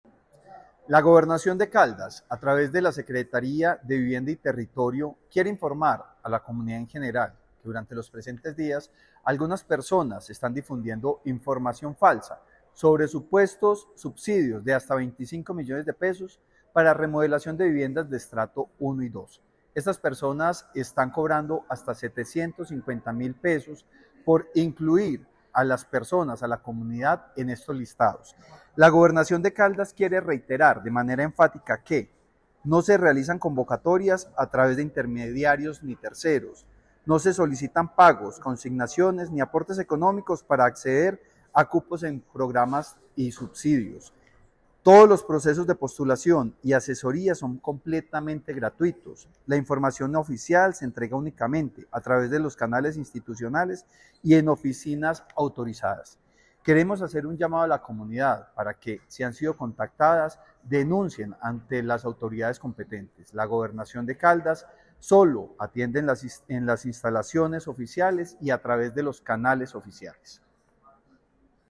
Francisco Javier Vélez Quiroga- Secretario de Vivienda y Territorio de Caldas.
FRANCISCO-JAVIER-VELEZ-QUIROGA-SECRETARIO-VIVIENDA-Y-TERRITORIO-DE-CALDAS-ESTAFA-PROGRAMAS-DE-VIVIENDA.mp3